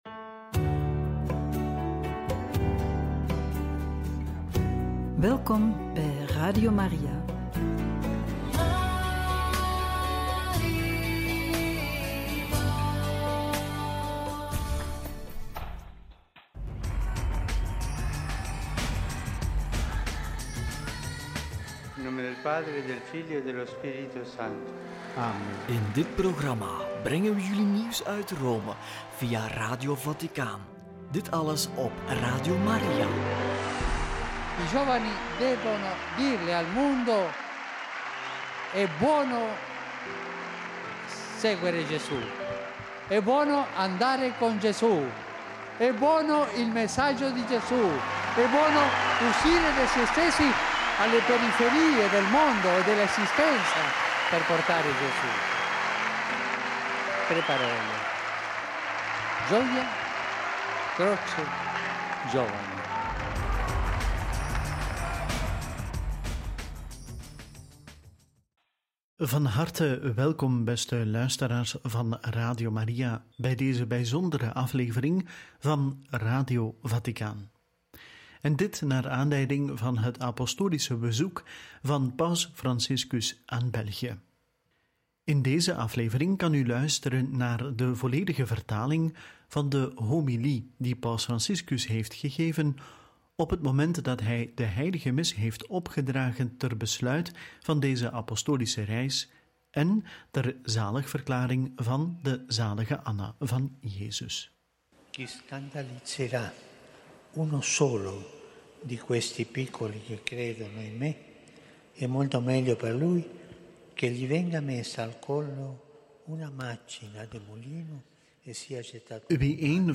29/9 Homilie paus Franciscus tijdens Eucharistie in het koning Boudewijn-stadion – Radio Maria
29-9-homilie-paus-franciscus-tijdens-eucharistie-in-het-koning-boudewijn-stadion.mp3